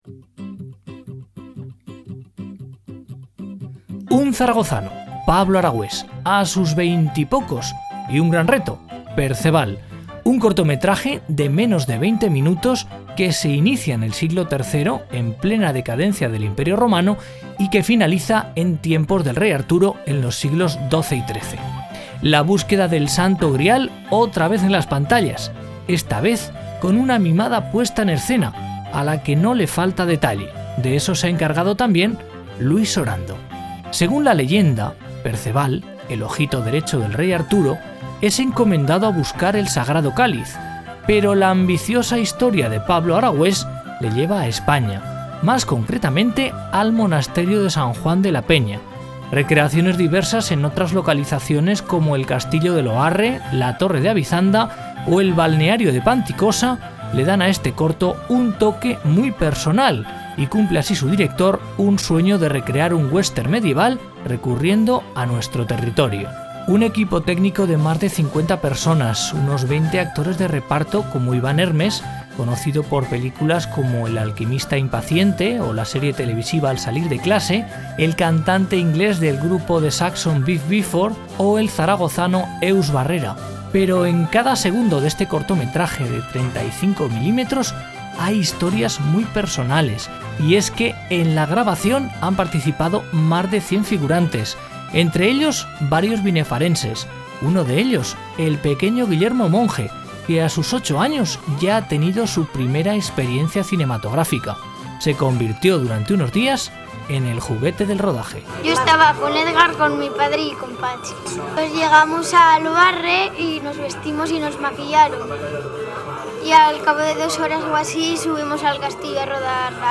Entrevistas emitidas en radio sobre el cortometraje documental.